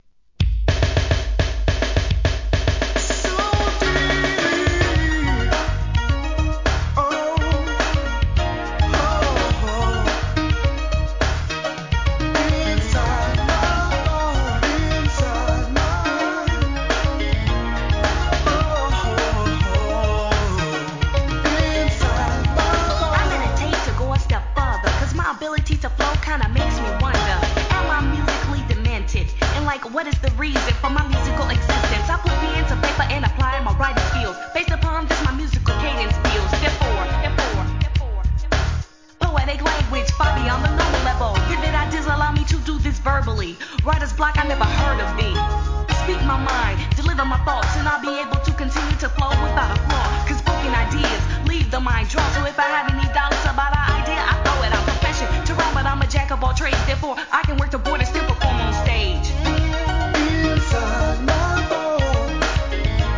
HIP HOP/R&B
マイナー・フィメールRAP! JAZZYなMOODで仕上げたNEW JACK SWING調のREMIXもGOOD!